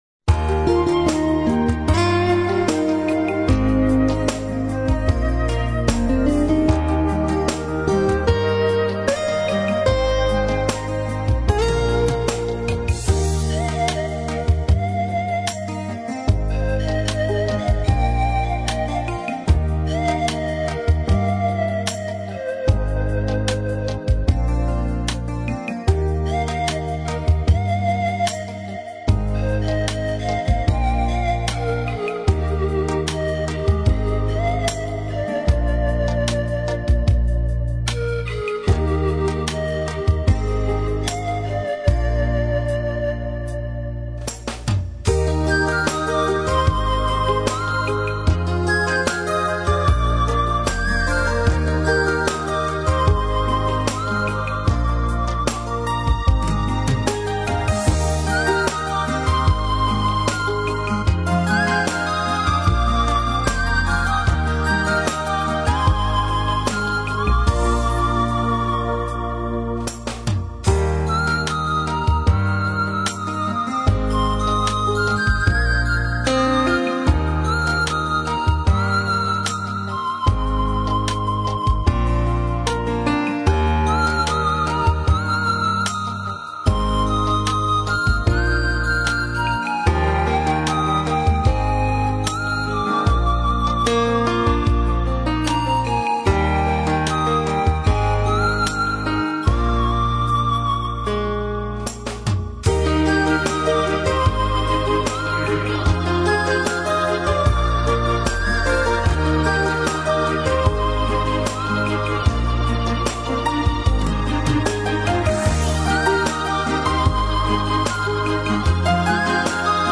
所属分类：轻 音 乐